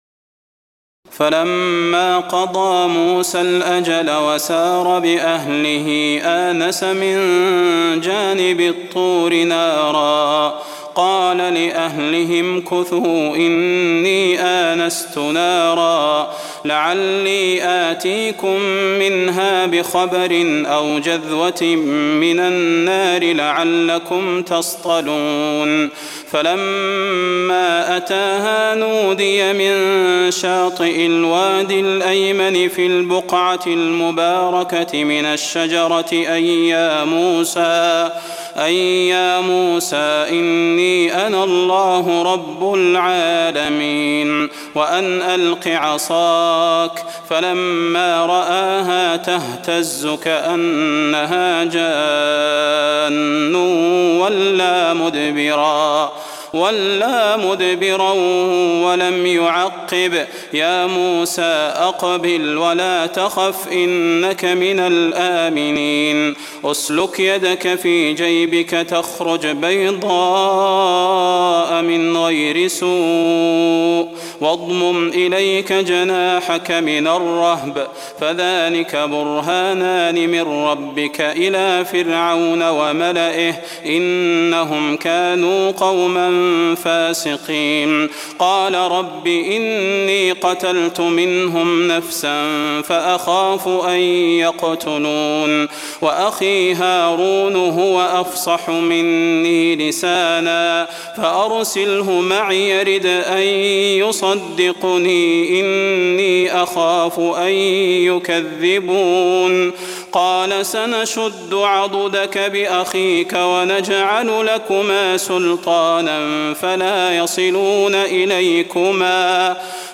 تراويح الليلة التاسعة عشر رمضان 1423هـ من سورة القصص (29-88) Taraweeh 19 st night Ramadan 1423H from Surah Al-Qasas > تراويح الحرم النبوي عام 1423 🕌 > التراويح - تلاوات الحرمين